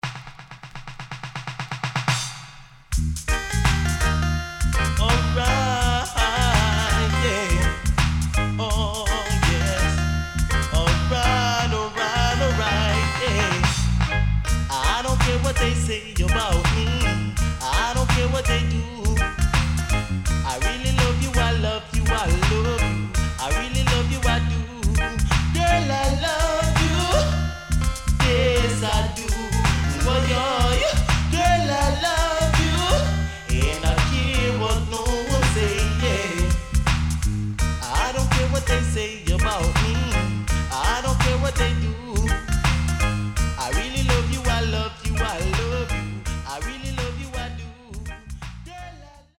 HOME > DISCO45 [DANCEHALL]